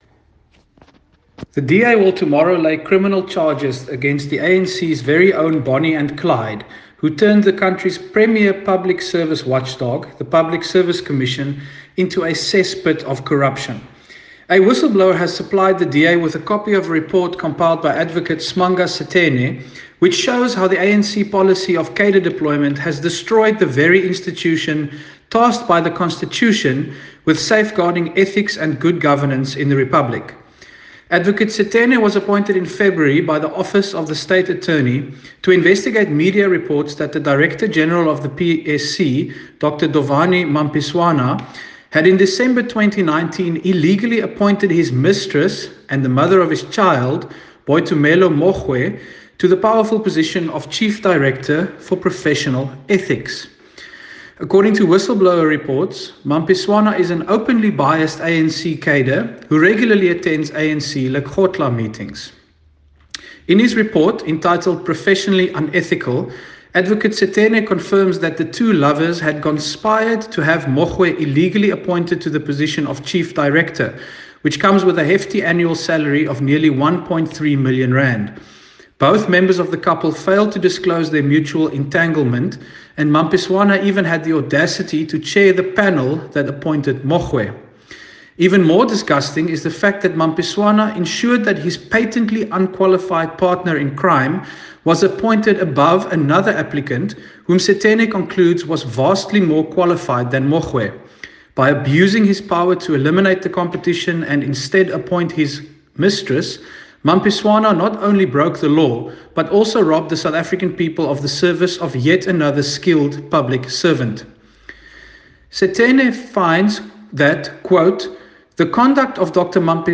soundbite by Dr Leon Schreiber MP, DA Shadow Minister for Public Service and Administration.
Dr-Leon-Schreiber-MP.mp3